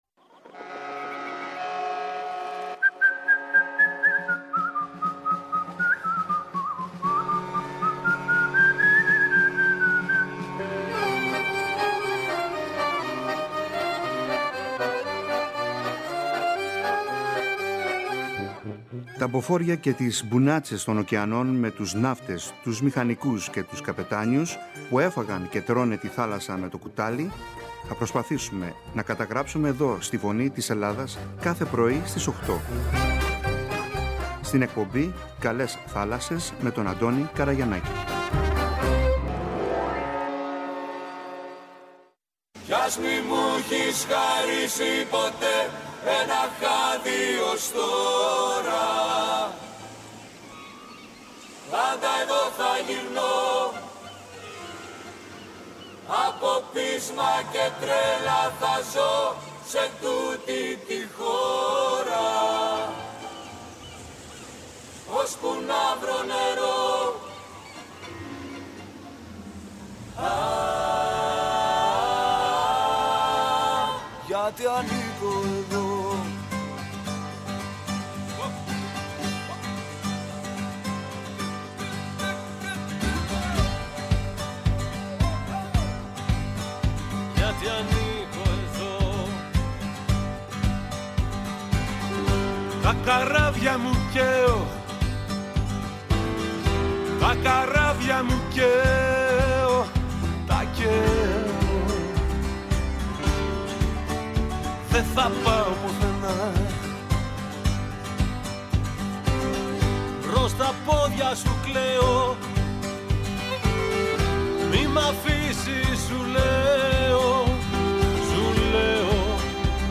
«Η Φωνή της Ελλάδας», συμμετείχε, για πρώτη φορά, με δικό της περίπτερο στη σημαντικότερη έκθεση Ναυτιλίας «Ποσειδώνια 2024».
Το δίωρο ραδιοφωνικό πρόγραμμα είχε συνεντεύξεις, παρουσιάσεις, χαιρετισμούς και μηνύματα από εκθέτες, φορείς, επισκέπτες, ανθρώπους της Ναυτιλίας και της θάλασσας.